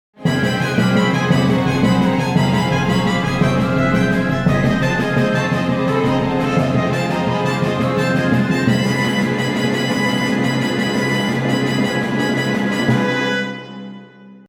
再生する凝縮された高密度なsoundに思わず惹き込まれる--繰り返される一定の波形が王冠を巡る壮絶な歴史を連想させる--狂気渦まく戦闘シーン--火刑に処されるジャンヌの果てしない苦悩と無念さ--天国に召されていく魂...祈り..